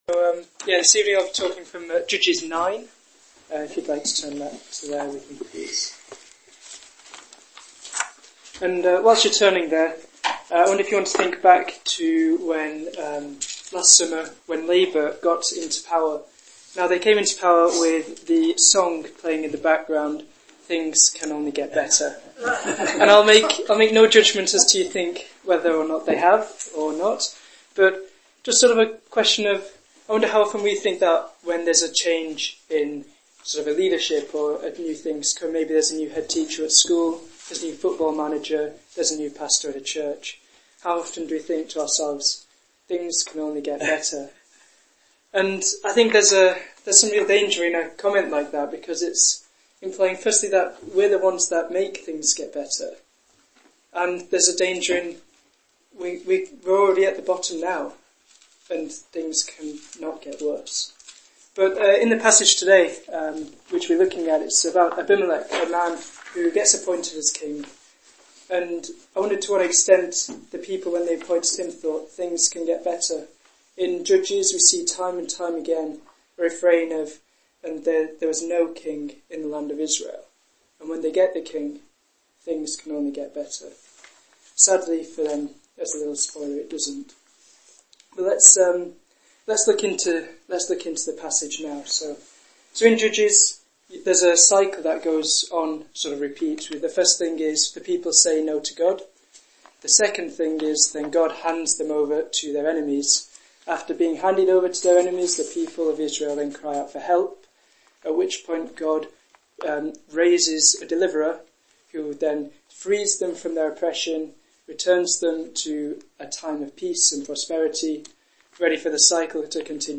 Sermon Podcasts Downloads | Salem Chapel, Martin Top